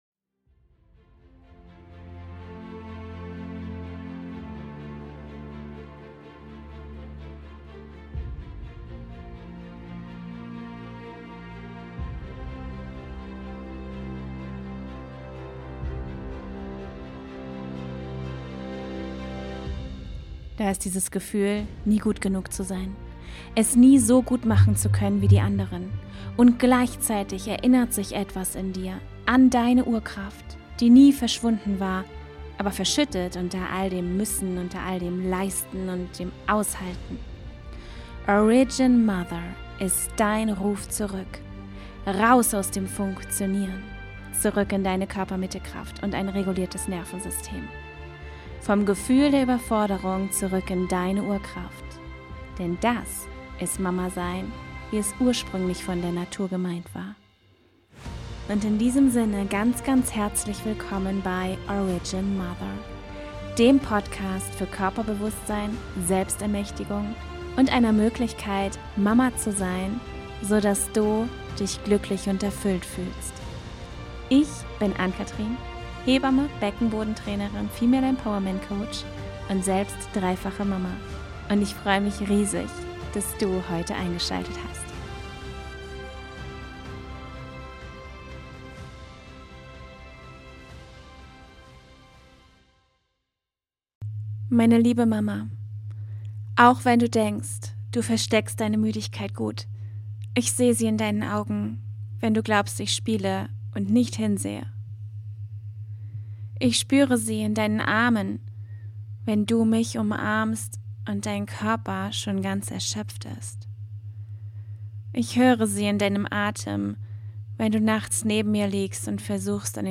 Ich lese dir ein Brief vor, wie er von deinem Kind aktuell